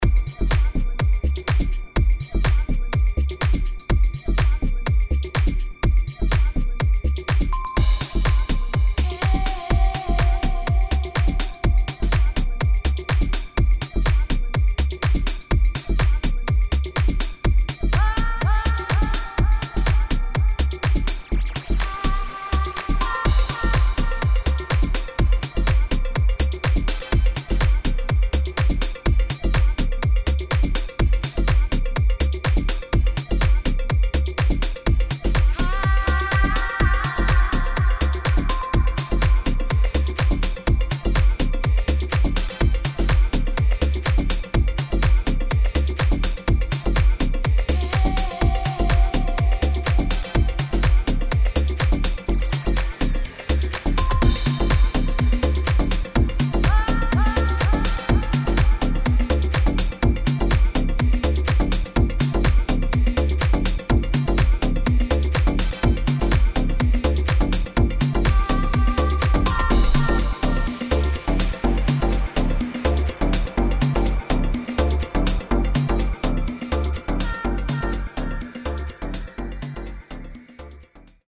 [ HOUSE ]